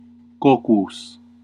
Ääntäminen
IPA: /ʃɛf/